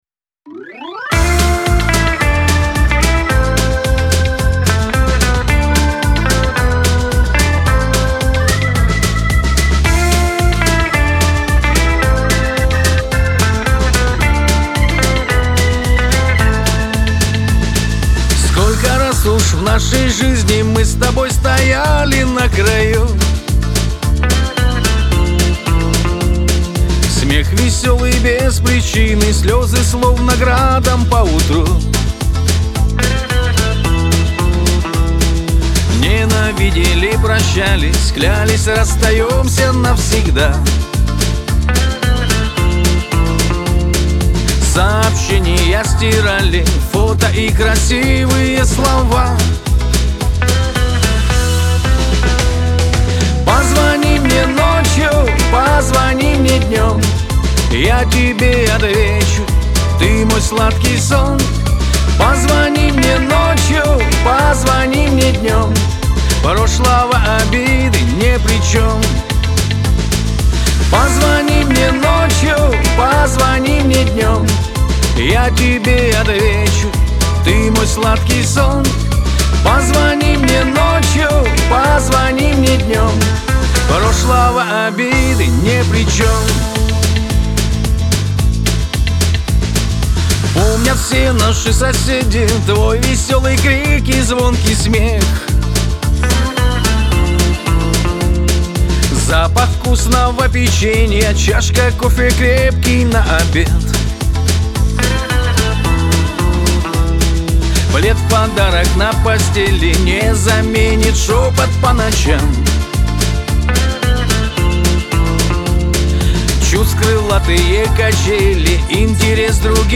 Лирика
pop